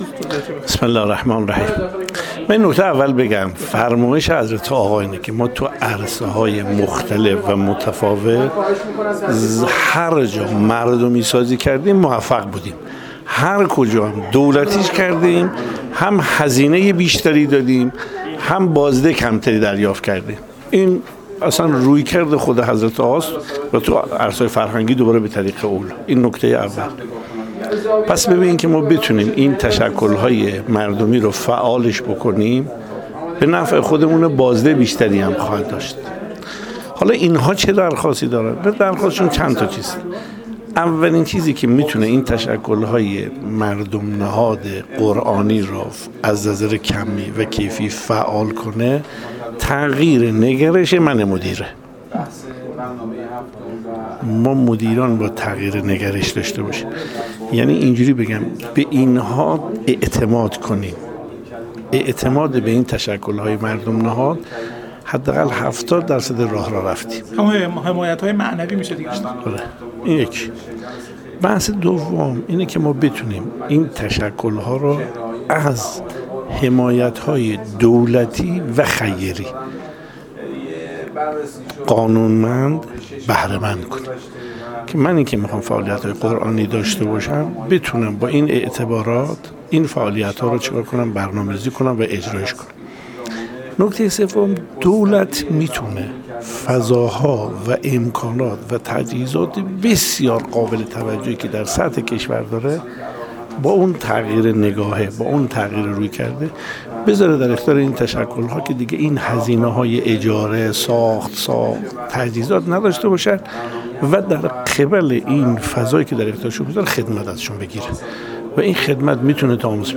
عضو فراکسیون قرآن و عترت مجلس بیان کرد: